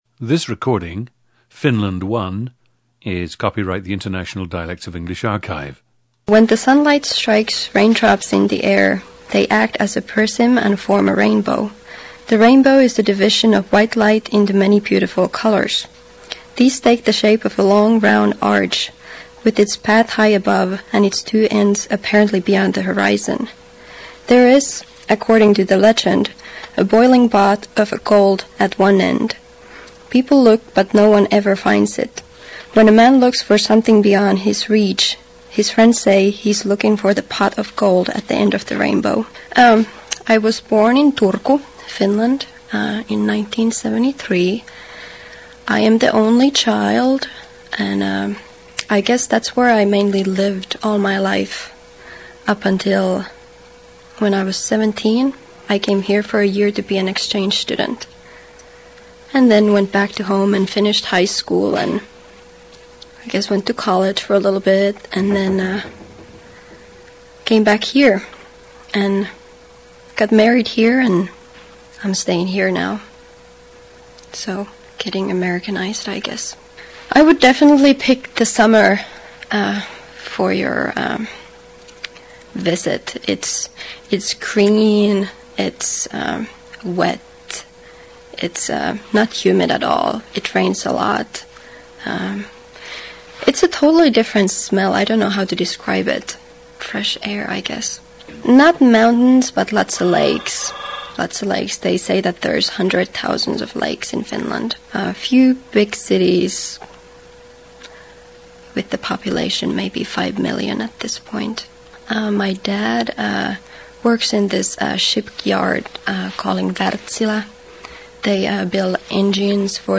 GENDER: female
The subject has a mild Finnish accent and feels she is quite “Americanized.”
• Recordings of accent/dialect speakers from the region you select.